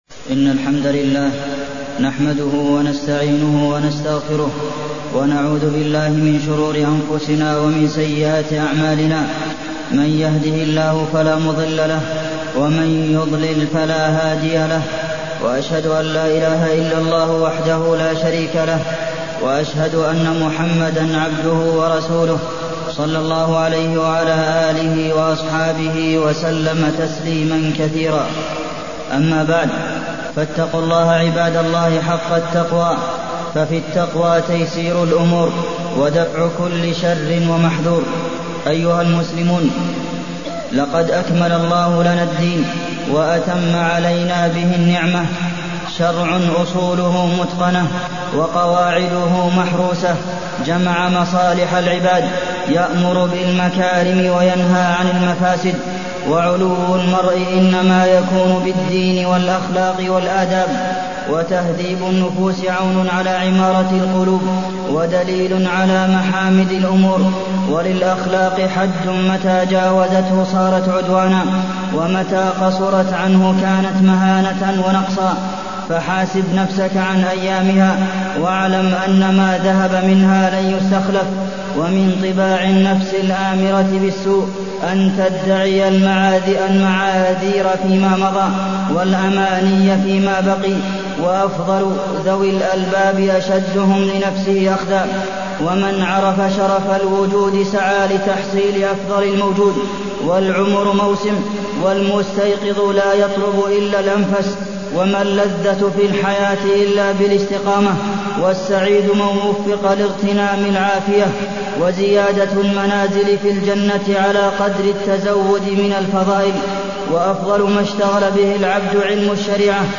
تاريخ النشر ٢٩ ربيع الثاني ١٤٢٢ هـ المكان: المسجد النبوي الشيخ: فضيلة الشيخ د. عبدالمحسن بن محمد القاسم فضيلة الشيخ د. عبدالمحسن بن محمد القاسم نصائح وتوجيهات